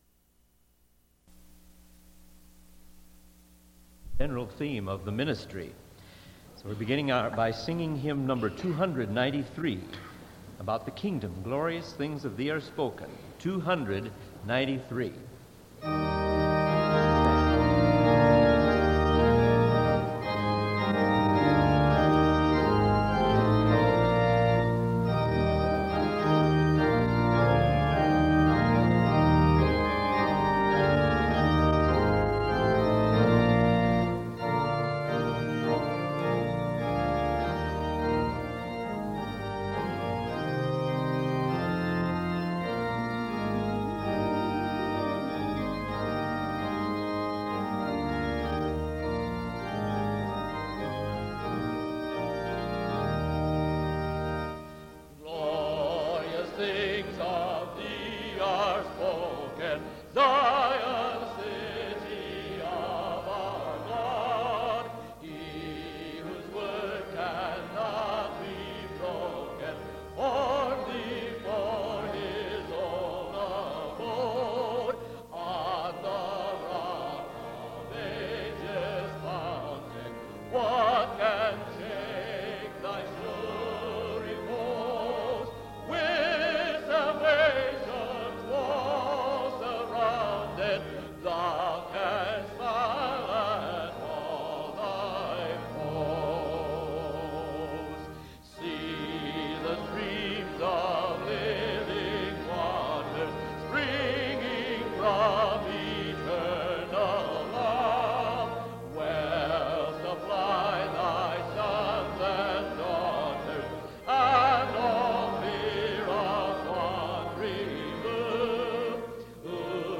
Faculty chapel services, 1975